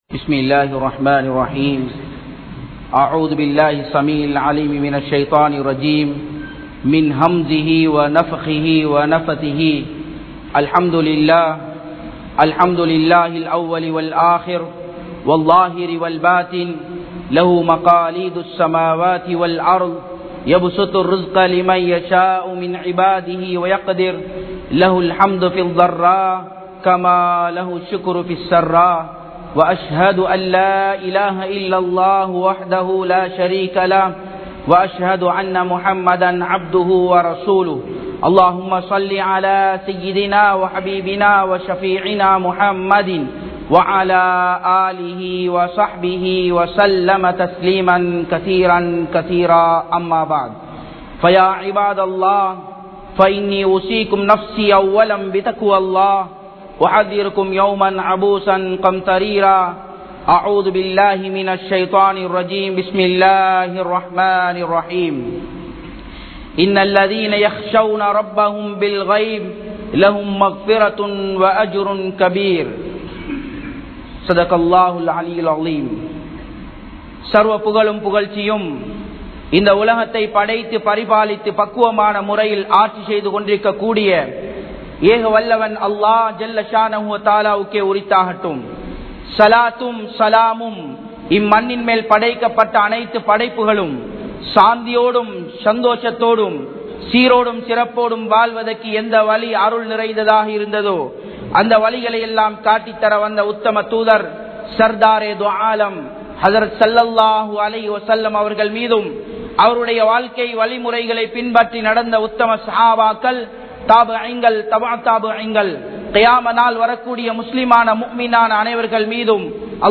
Panagalai Paavaththitku Selavalikkatheerhal (பணங்களை பாவத்திற்கு செலவழிக்காதீர்கள்) | Audio Bayans | All Ceylon Muslim Youth Community | Addalaichenai
Muhiyadeen Jumua Masjith